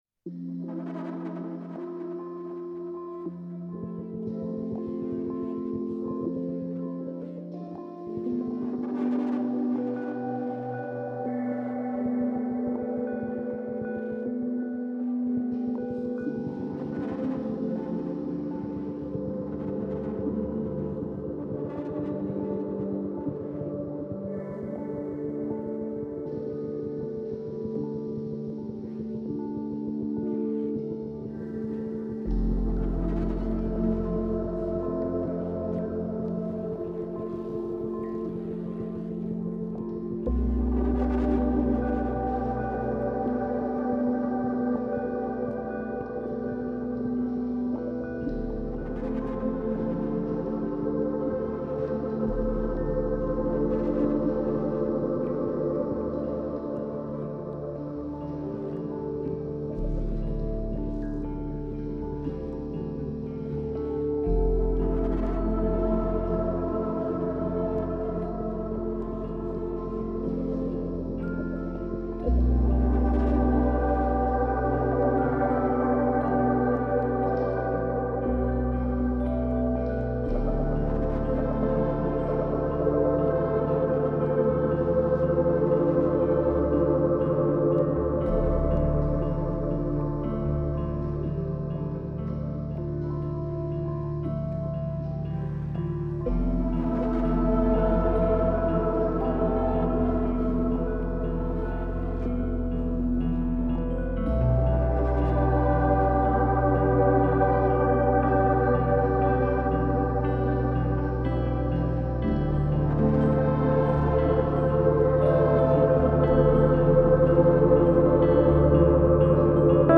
Textural layers, tones and natural atmosphere.